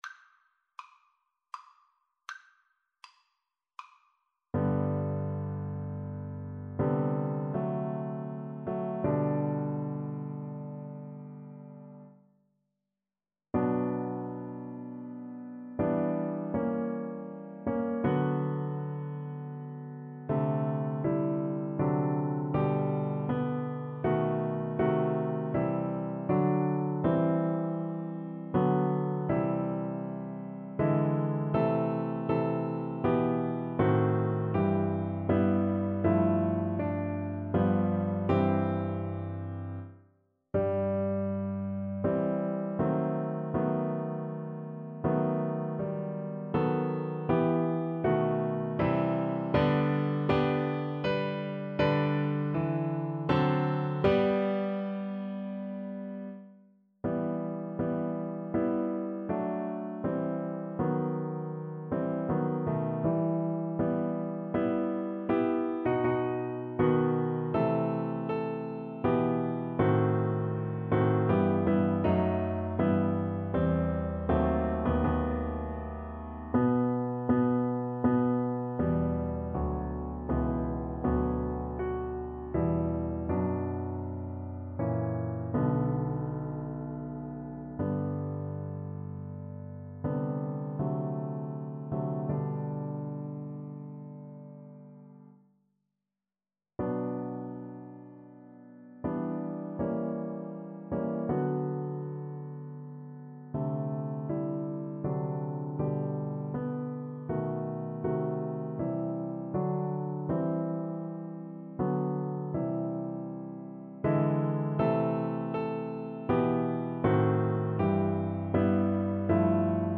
~ = 80 Andante ma non lento
3/4 (View more 3/4 Music)
Classical (View more Classical Flute Music)